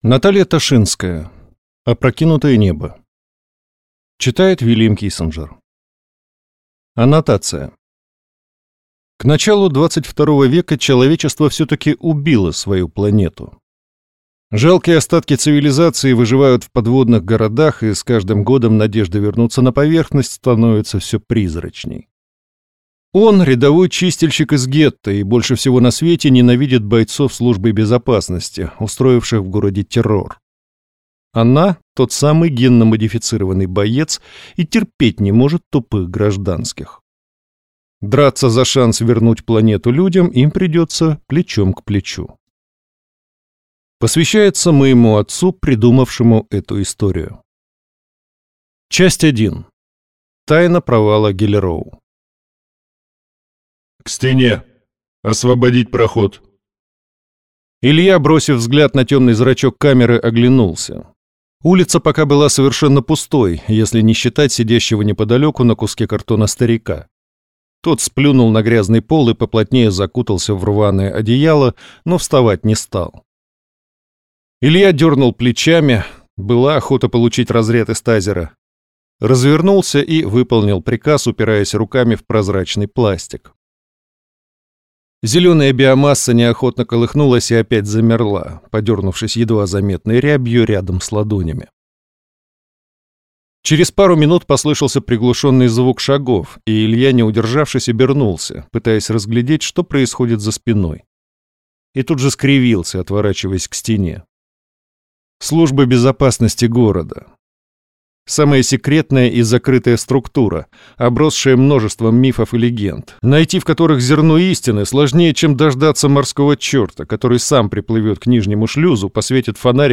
Аудиокнига Опрокинутое небо | Библиотека аудиокниг